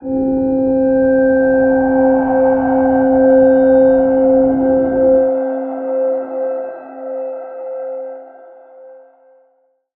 G_Crystal-C5-mf.wav